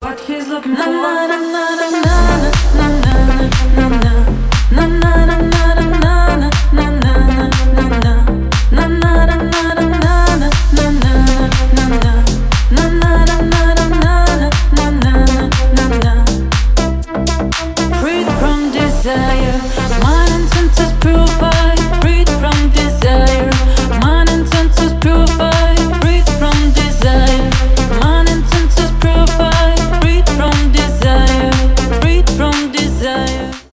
клубные
club house
deep house